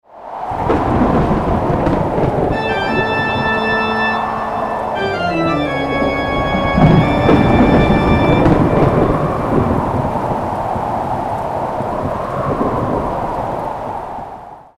Thunderstorm-and-rain-with-spooky-church-organ-sound-effect.mp3